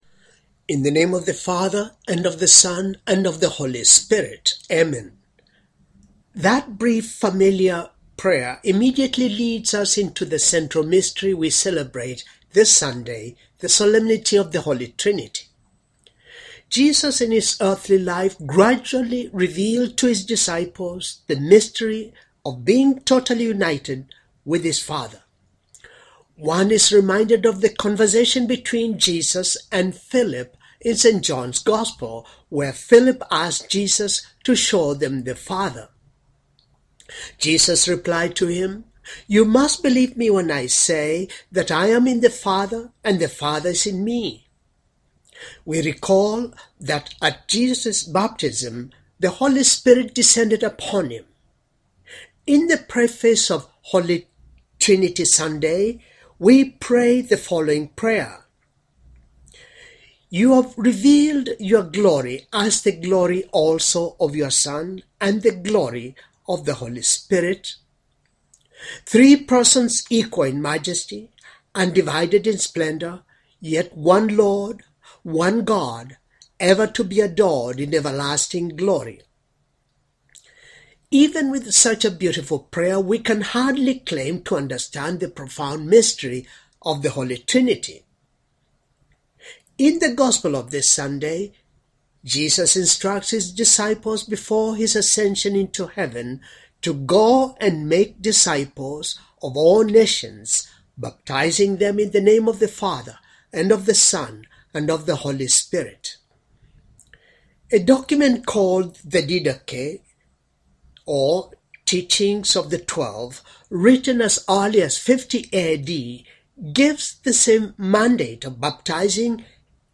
Homily for Trinity Sunday